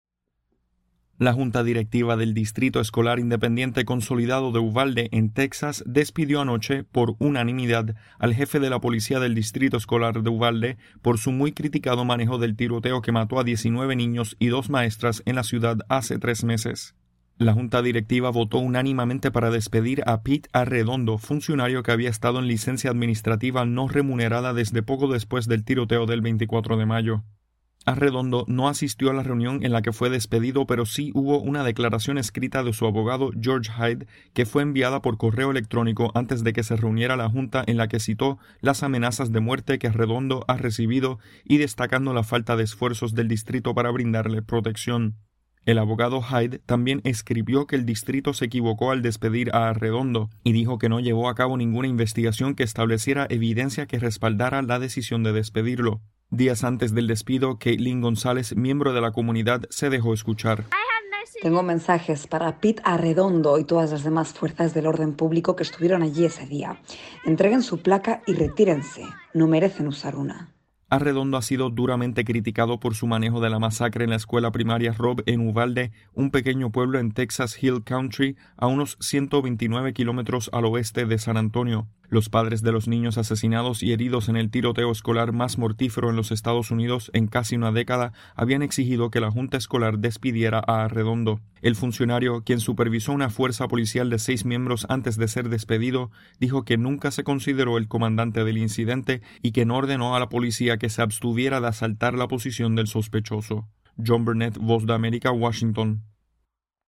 La Junta Escolar de Uvalde en Texas despide al jefe de la Policía del Distrito por su manejo del ataque armado ocurrido en la escuela elemental Robb. Informa